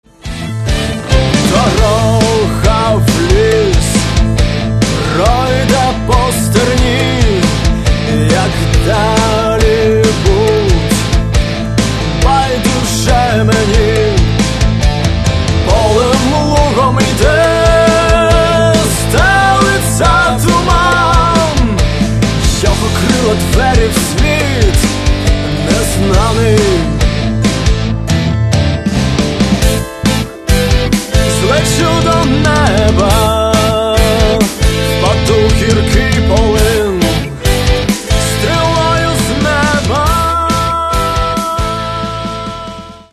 альтернативного фолк-рока